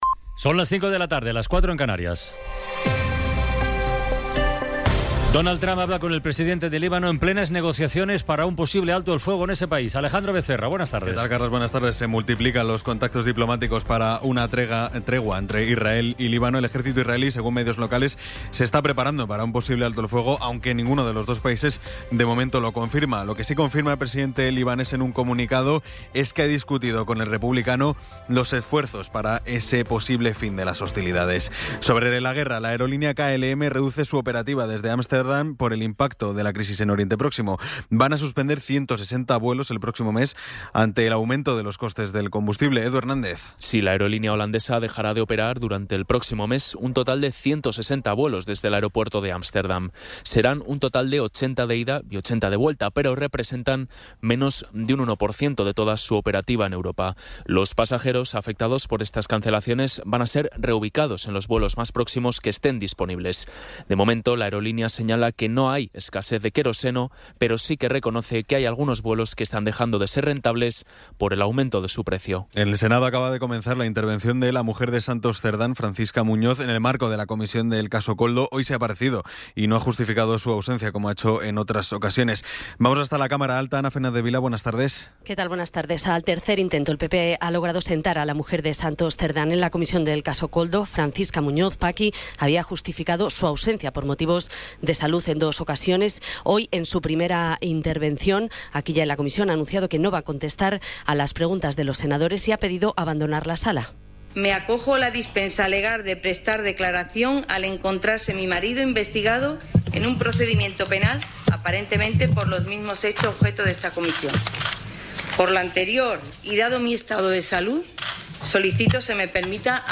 Resumen informativo con las noticias más destacadas del 16 de abril de 2026 a las cinco de la tarde.